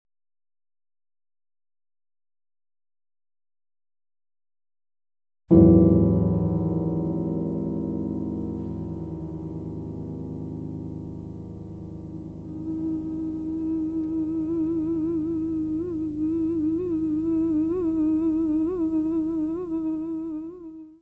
Gravado no estúdio Radio DRS, Berna, em Junho de 1994
Área:  Música Clássica